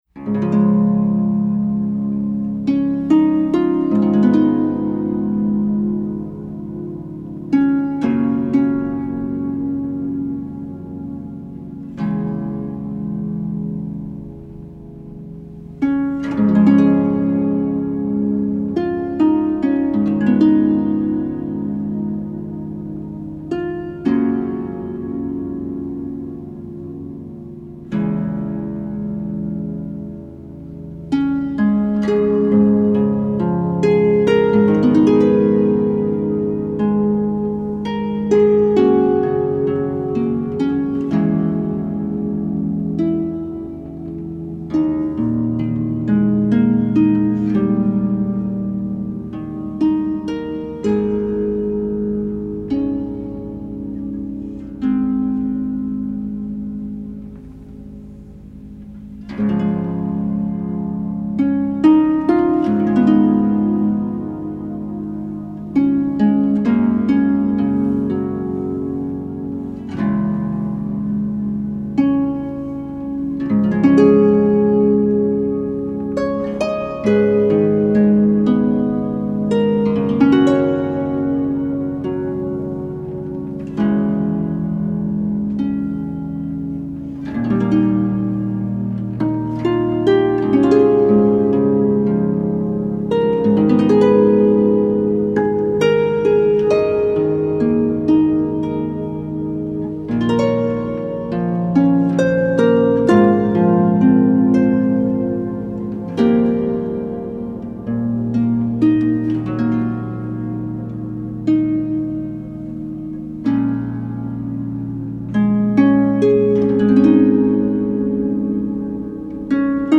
solo pedal harp. This hopeful work